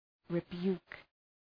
rebuke.mp3